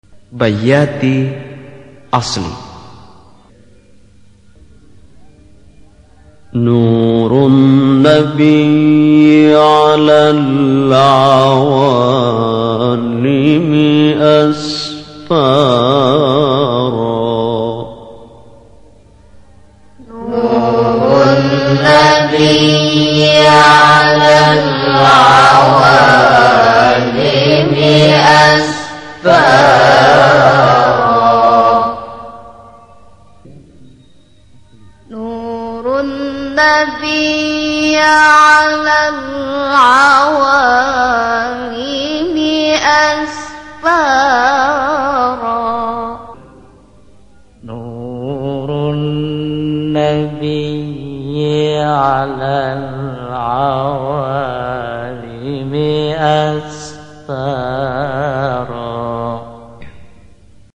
بیات-اصلی-قرار1.mp3